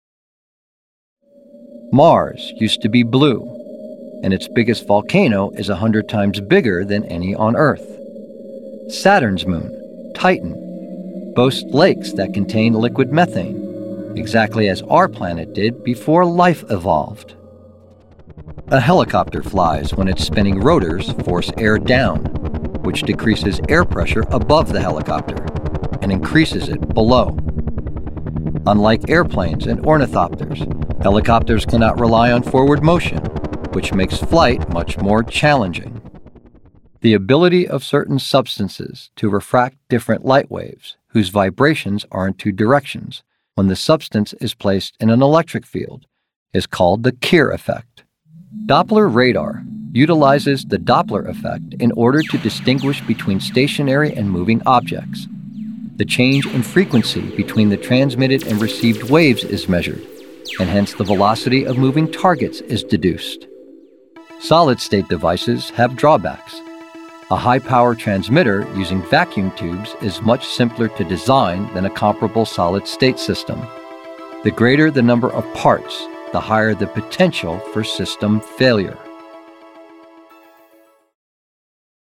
Explainer
English - Midwestern U.S. English
Middle Aged
Senior